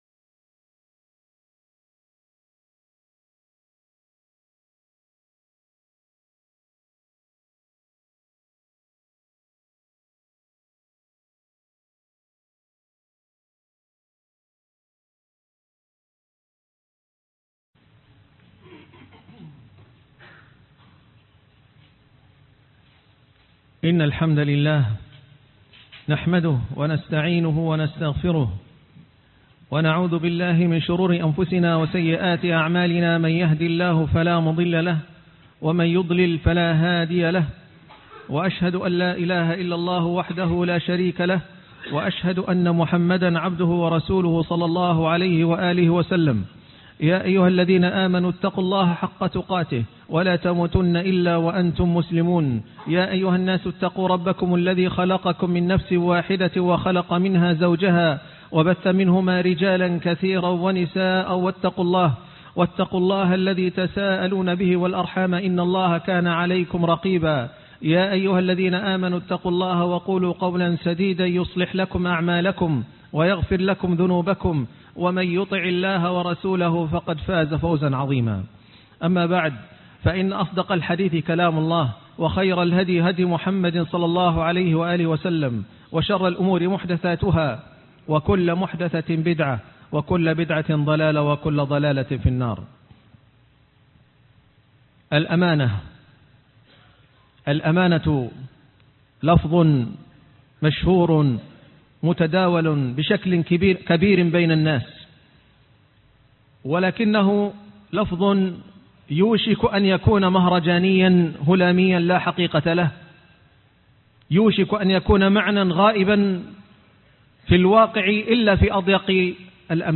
الأمانة _ خطبة الجمعة